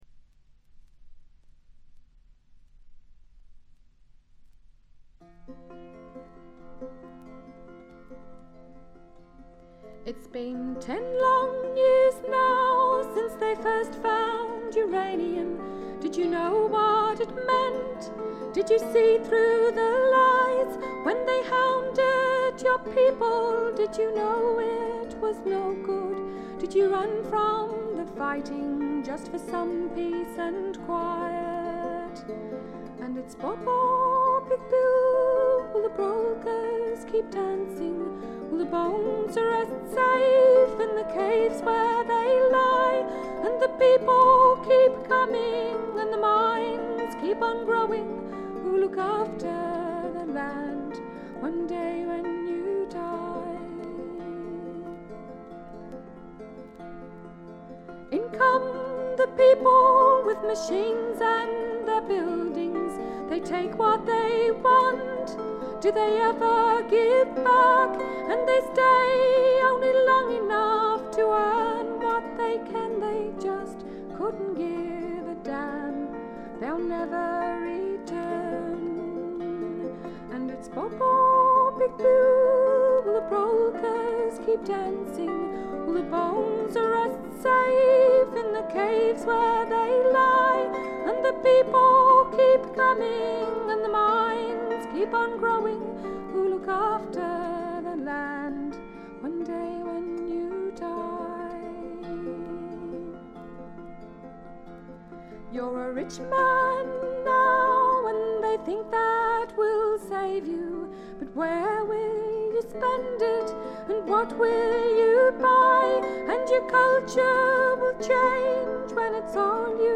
トラディショナル・ソングとトラッド風味の自作曲を、この上なく美しく演奏しています。
試聴曲は現品からの取り込み音源です。
vocal, harp, banjo
viola.